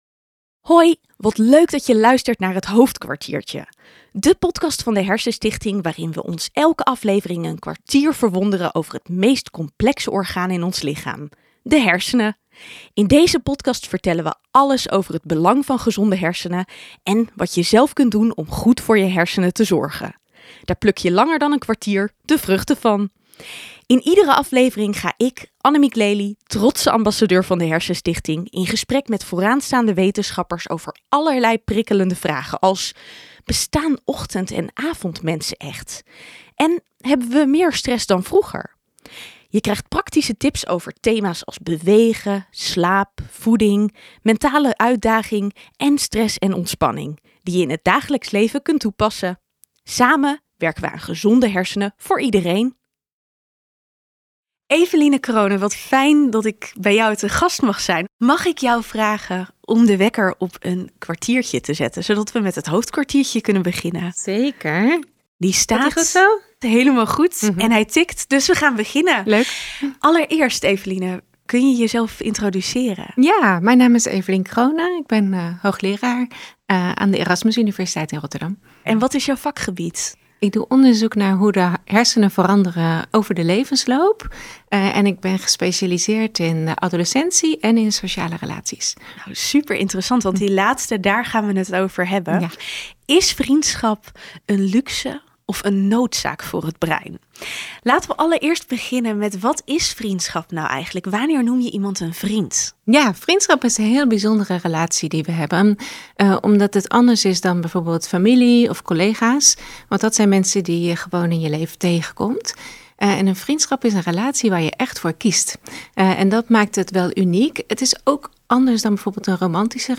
Hoogleraar ontwikkelingspsychologie Eveline Crone vertelt over de effecten van vriendschap op onze hersenen gedurende ons leven en op onze mentale gezondheid. Is er een verschil tussen vriendschap en andere sociale contacten? Wat is de invloed van vriendschap als je je eenzaam voelt?